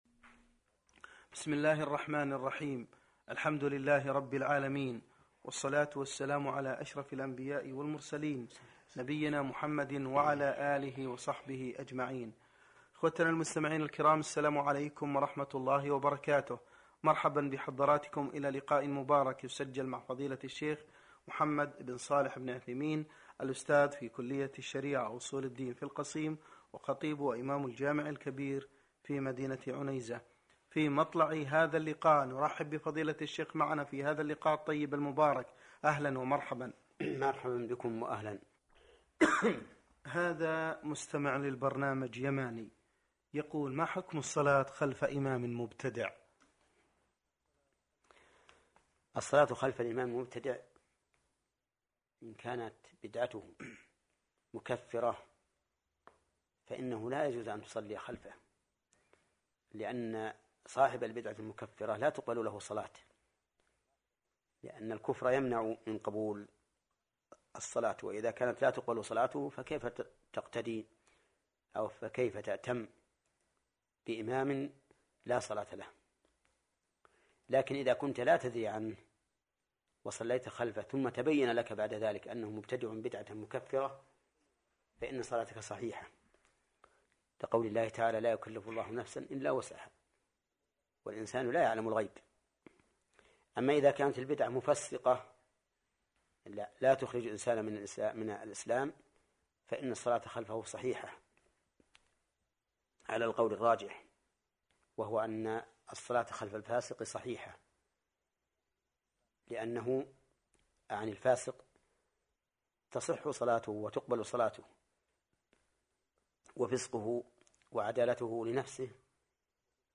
A listener of the program from Yemen asks: What is the ruling on praying behind an imam who is an innovator (Mubtadi')?